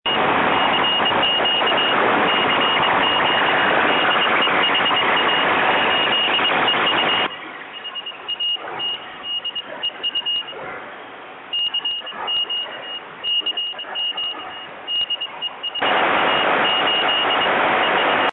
la riduzione del rumore è drastica ed il segnale diventa ben comprensibile.
Tali files sono stati registrati in situazioni reali, sulle bande radioamatoriali.